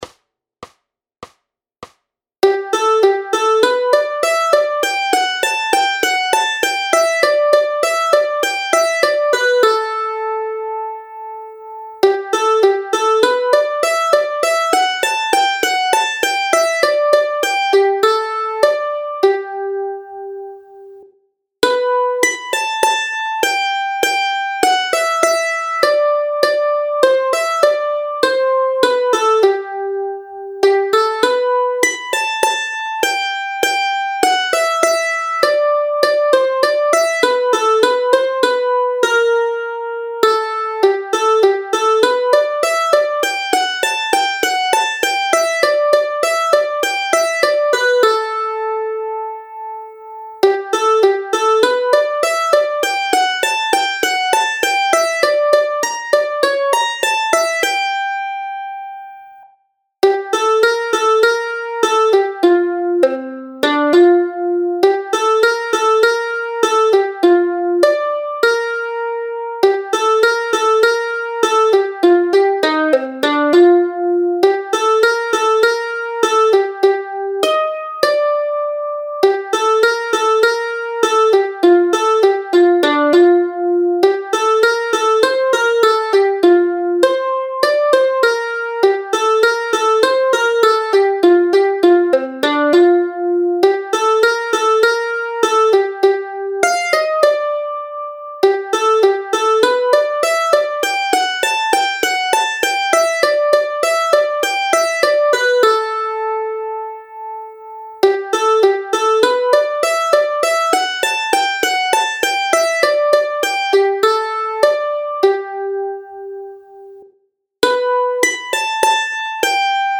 Aranžmá Noty, tabulatury na mandolínu
Formát Mandolínové album
Hudební žánr Klasický